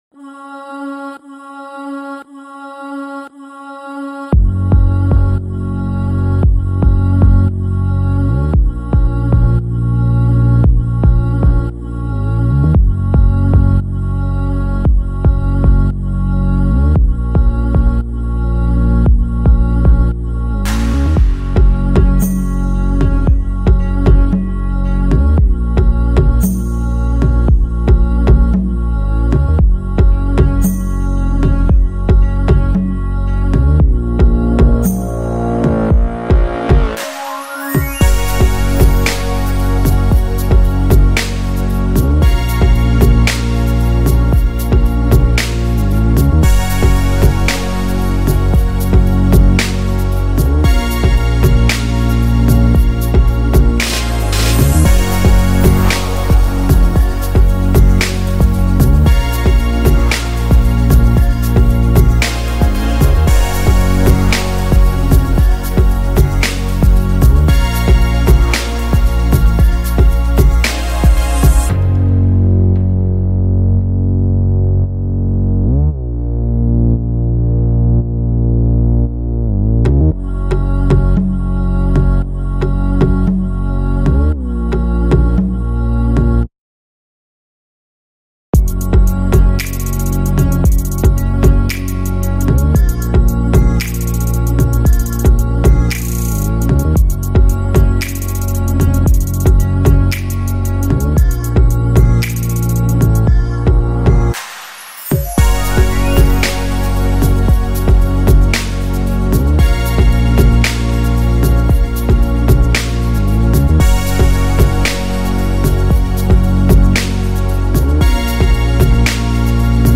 invitation-instrumental.mp3